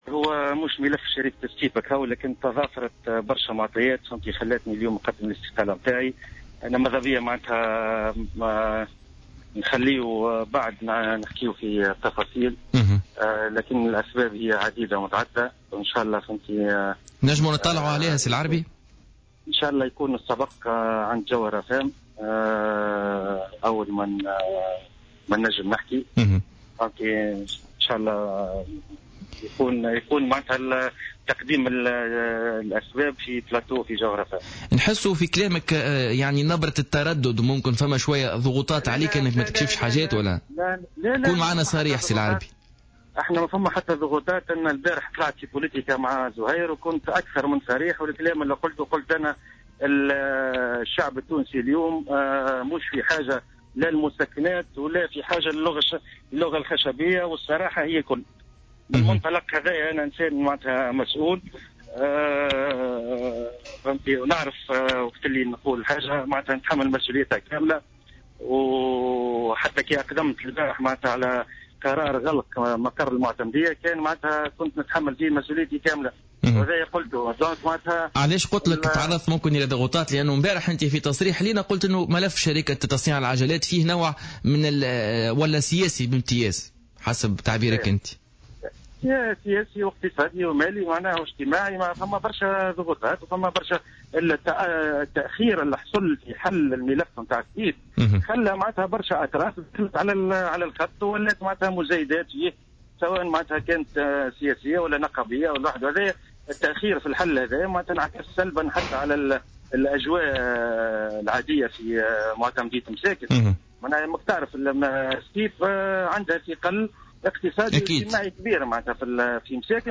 وأضاف في مداخلة له اليوم في برنامج "بوليتيكا" أن ملف شركة "ستيب" من بين الأسباب التي جعلته يقدم استقالته، مشيرا إلى أنه وجد نفسه وحيدا في مواجهة احتقان العمال في ظل غياب أي حل جذري ينهي هذه الأزمة.